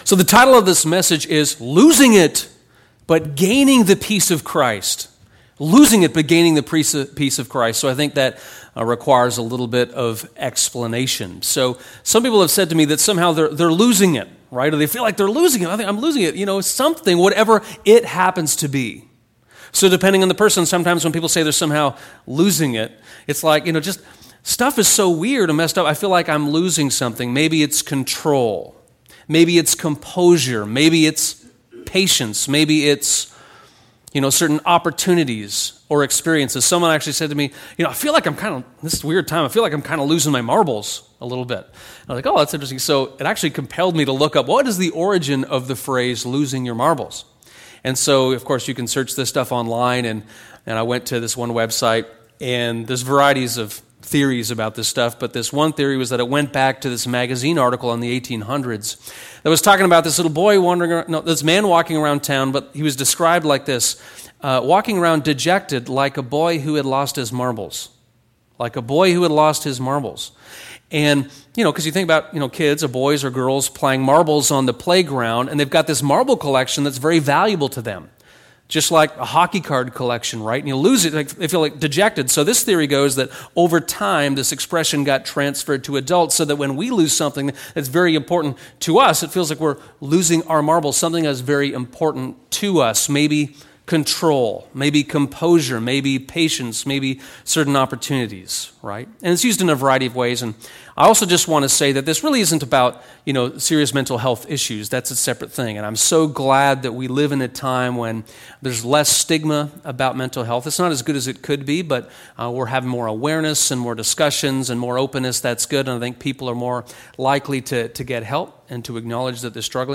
In this sermon from July 19, 2020, I explore Colossians 3:12-17 and the idea that you can’t have everything that matters, but you can have everything that matters most—specifically, the peace of Christ.